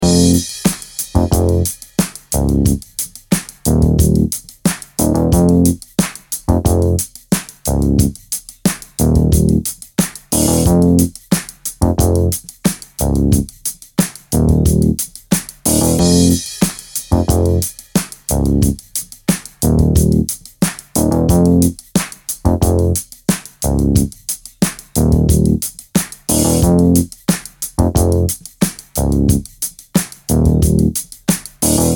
latin neo-soul tracks